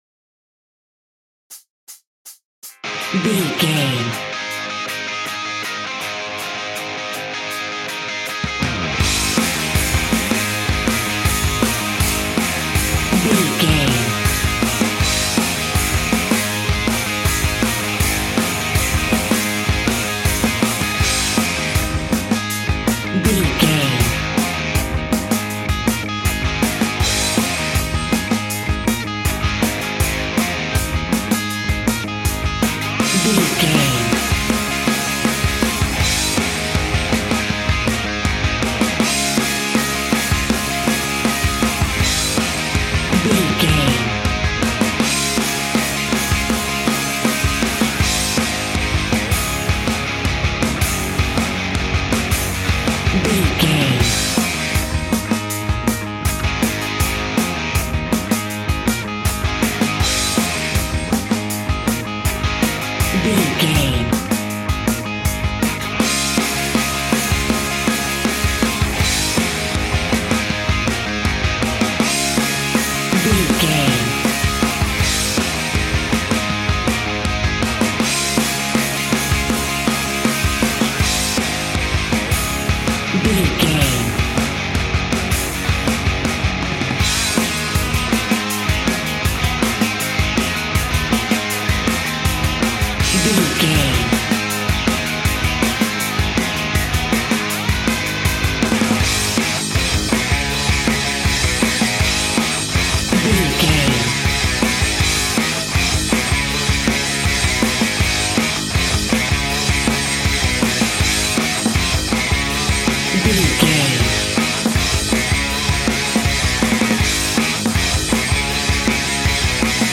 Epic / Action
Fast paced
Ionian/Major
A♭
hard rock
blues rock
rock guitars
Rock Bass
Rock Drums
distorted guitars
hammond organ